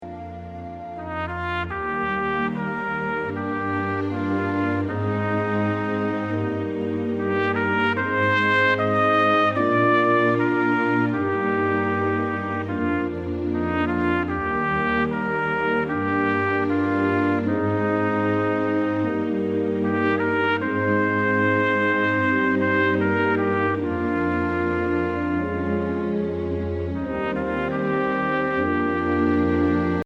Voicing: Trumpet Collection